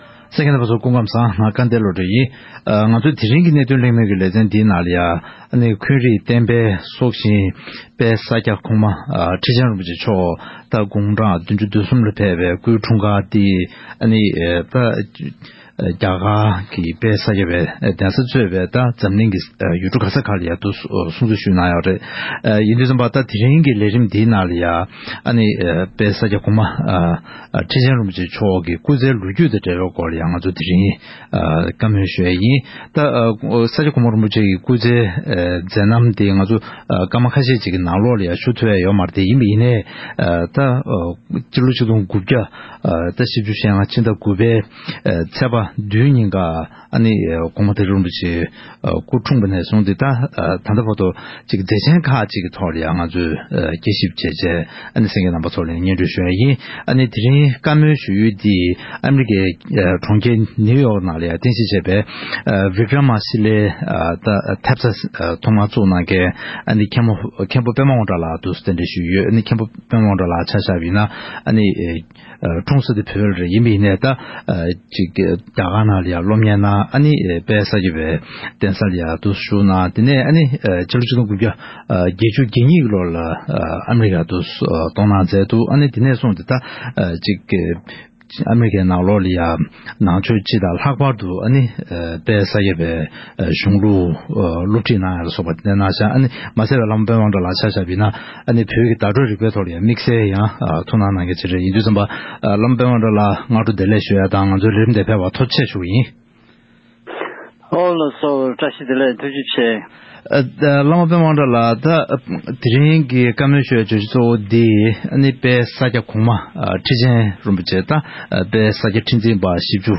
༄༅། །དཔལ་ས་སྐྱ་༸གོང་མ་ཁྲི་ཆེན་རིན་པོ་ཆེ་མཆོག་དགུང་གྲངས་༧༣ཕེབས་པའི་སྐུའི་འཁྲུངས་སྐར་གྱི་དུས་ཆེན་དེ་བཞིན་རྒྱ་གར་གཙོས་པའི་འཛམ་བུ་གླིང་གི་ཡུལ་གྲུ་འདྲ་མིན་ནང་སྲུང་བརྩི་ཞུས་ཡོད་པ་ལྟར། དེ་རིང་གི་གནད་དོན་གླེང་མོལ་གྱི་ལེ་ཚན་ནང་༸གོང་མ་ཁྲི་ཆེན་རིན་པོ་ཆེ་མཆོག་གི་མཛད་པ་འཕྲིན་ལས་དང་འབྲེལ་བའི་སྐོར་ལ་གླེང་མོལ་ཞུས་པ་ཞིག་གསན་རོགས་གནང་།